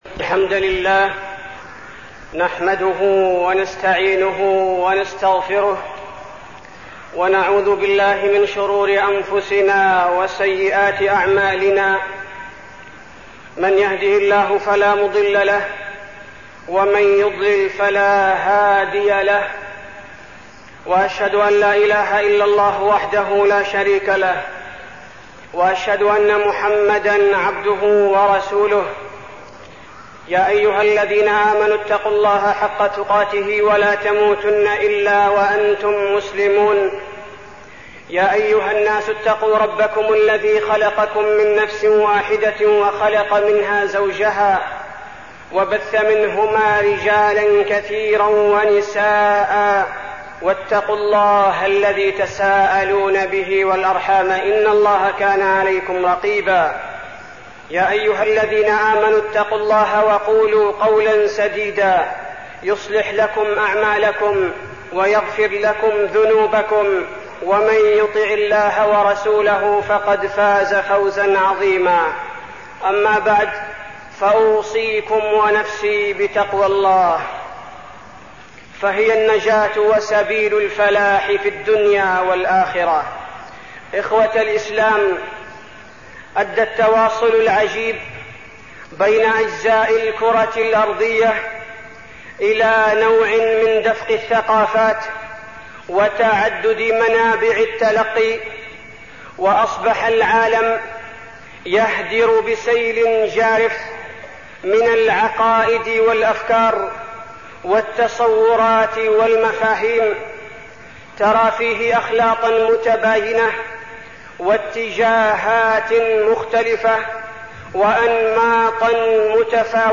تاريخ النشر ٢٥ ربيع الأول ١٤١٧ هـ المكان: المسجد النبوي الشيخ: فضيلة الشيخ عبدالباري الثبيتي فضيلة الشيخ عبدالباري الثبيتي سمات الشخصية الإسلامية